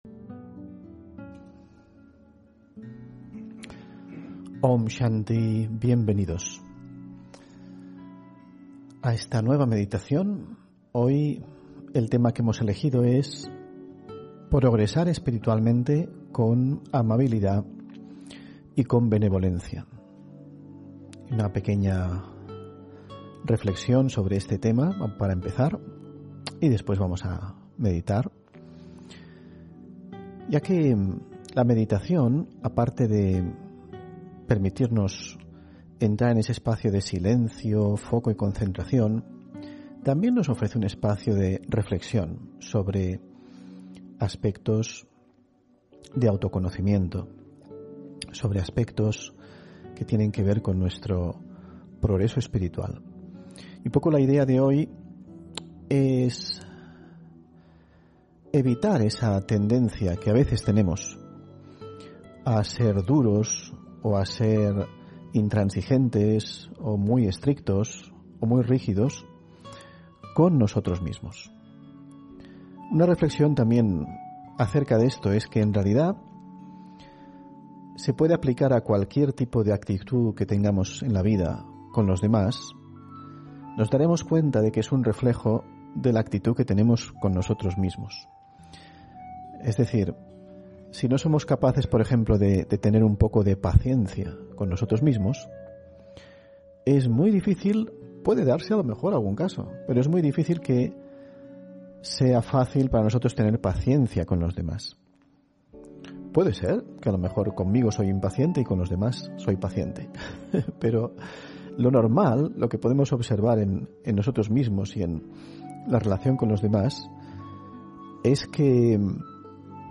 Meditación y conferencia: Progresar espiritualmente con amabilidad y benevolencia (11 Enero 2022)